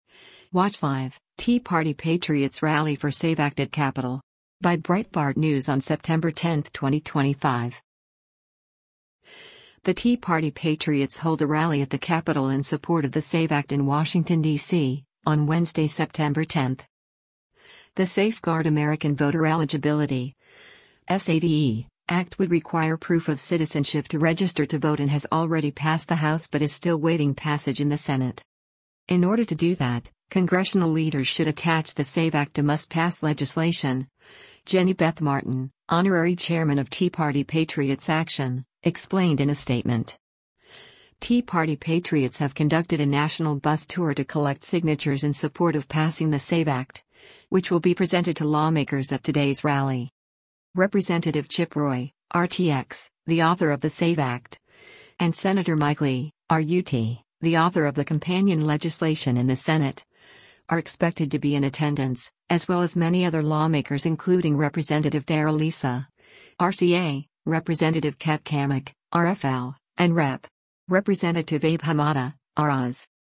The Tea Party Patriots hold a rally at the Capitol in support of the SAVE Act in Washington, DC, on Wednesday, September 10.